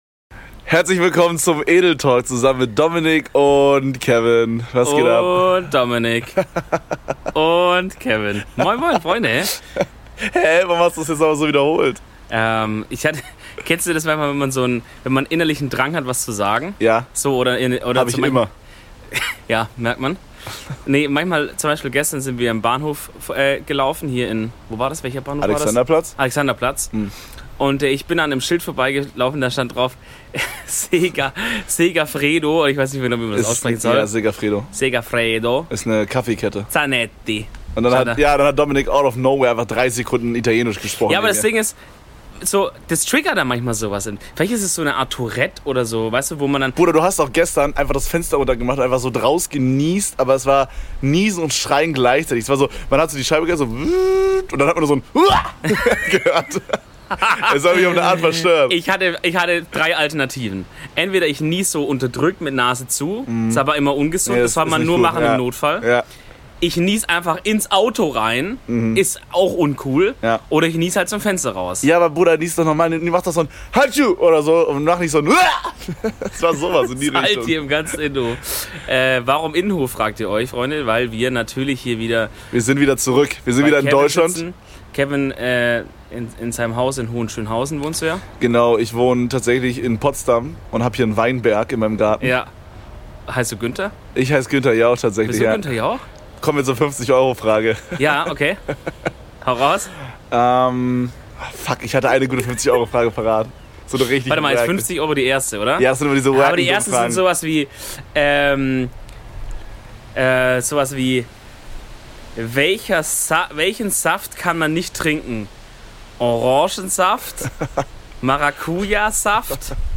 Die Atmo ist gratis und der Content wie immer premium!